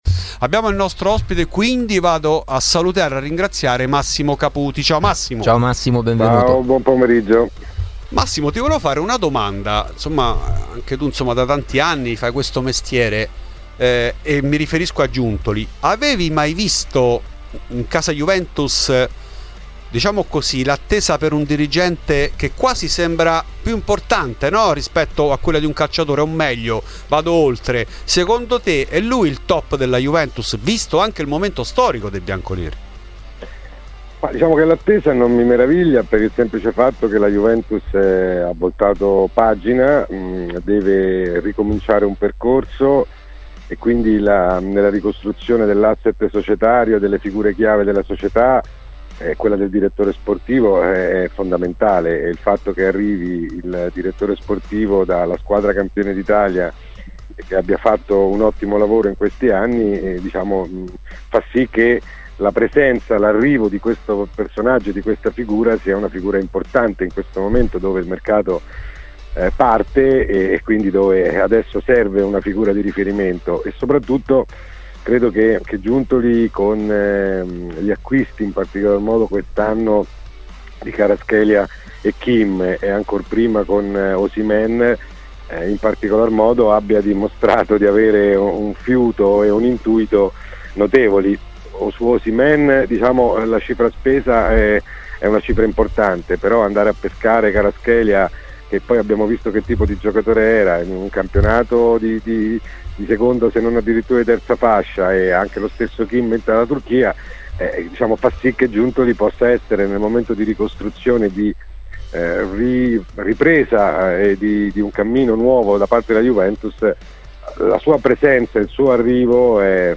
Intanto tempi stretti per la sentenza della Uefa sulla possibile violazione del Fair Play Finanziario. In ESCLUSIVA a Fuori di Juve il giornalista